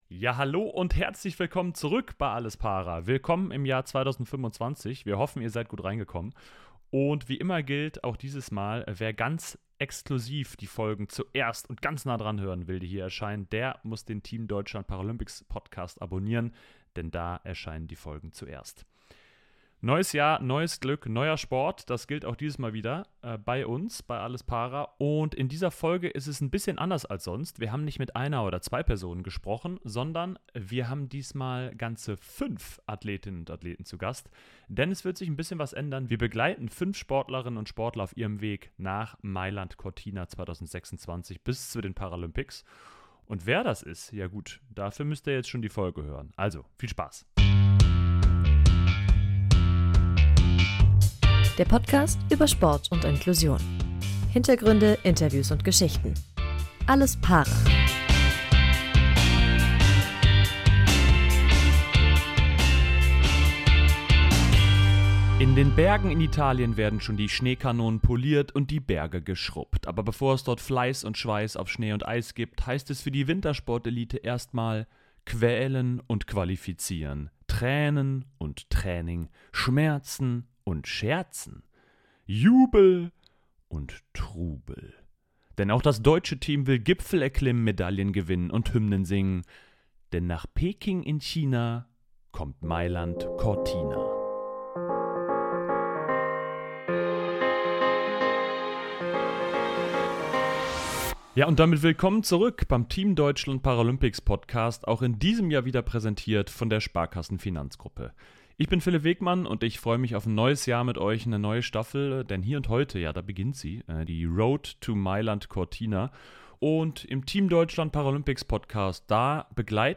In der ersten Folge der neuen Staffel sprechen die fünf über ihre Herausforderungen auf dem Weg nach Mailand-Cortina, welche Personen für sie dafür wichtig sind und wo sie sportlich aktuell stehen. Weil es aber so viel mehr über diese fünf Athlet*innen zu wissen gibt, begleiten wir sie ab jetzt bis zu den Paralympics 2026 in Italien.